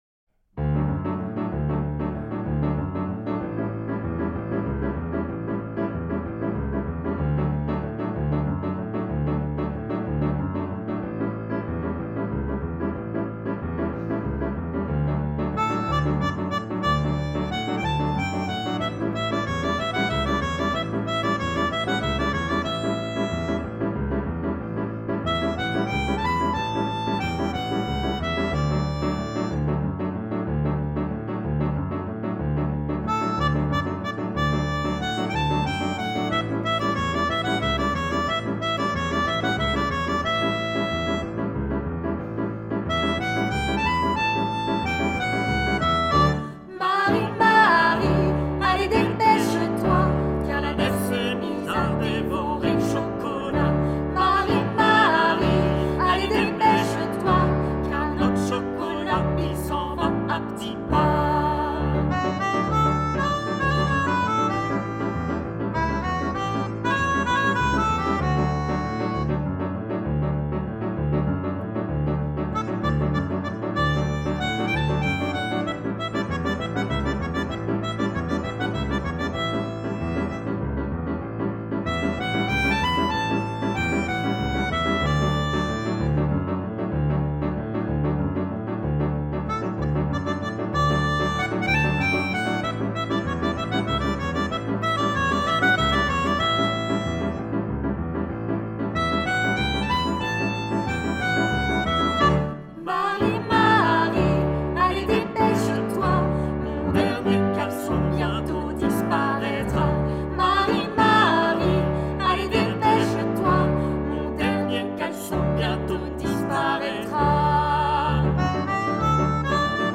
Genre :  ChansonComptine
Style :  Avec accompagnement
Une chanson à l'atmosphère chaleureuse et narrative, parfaite pour la période hivernale !
Enregistrement tutti intro longue